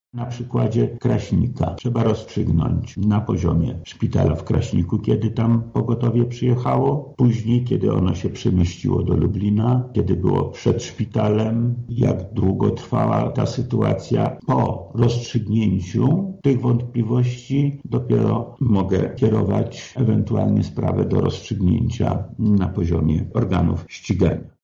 Karetka pogotowia nie może być szpitalem na kółkach – mówi wojewoda lubelski Lech Sprawka: